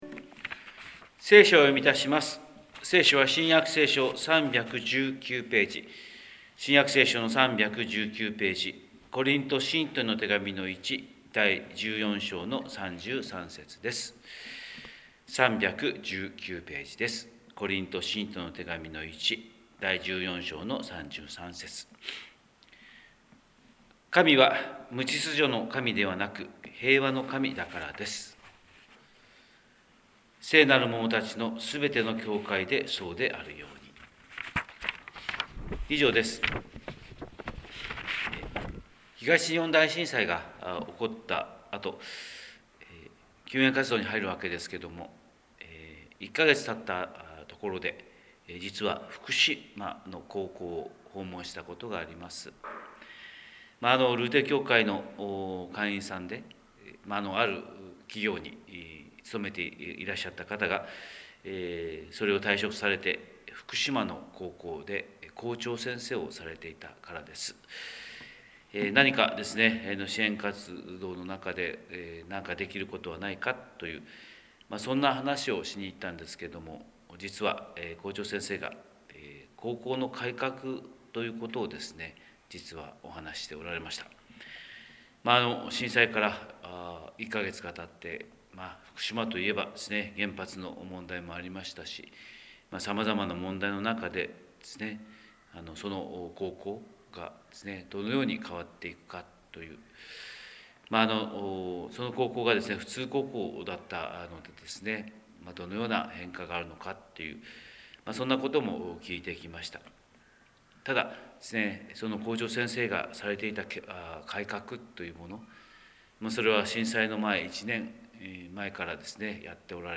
神様の色鉛筆（音声説教）: 広島教会朝礼拝250226